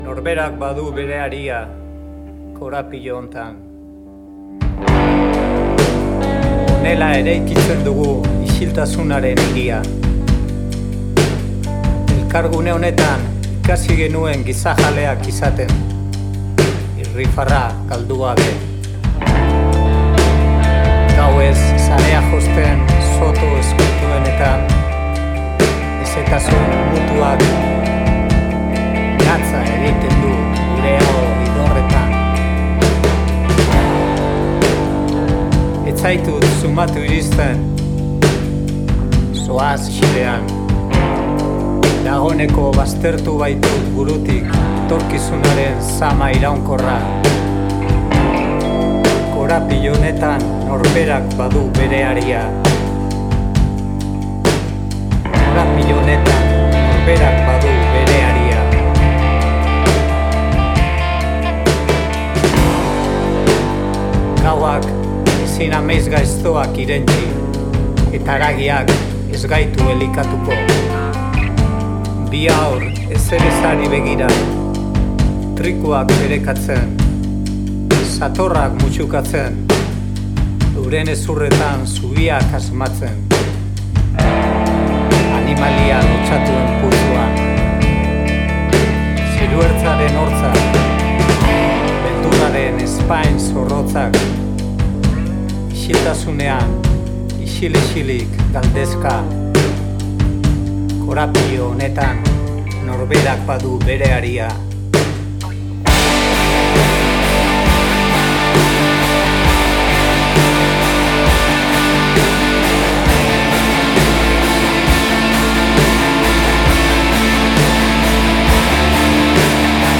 post-Rock